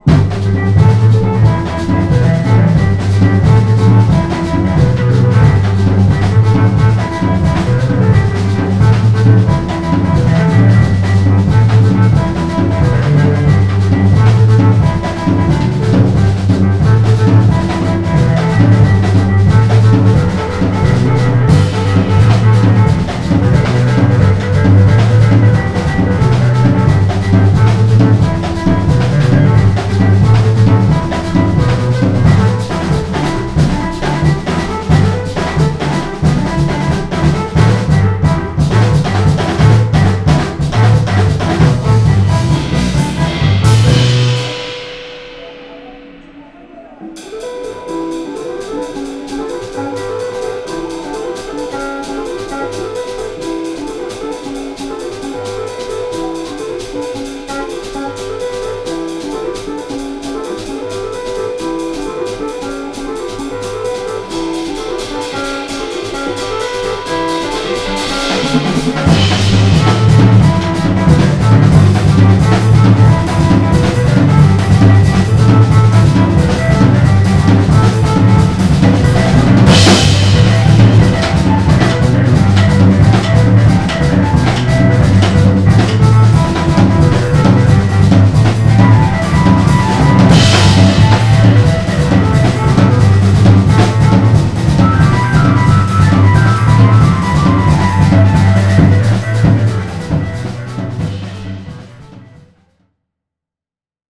Duo
Performing an original composition based on Sabar rhythms at The Oxford, London.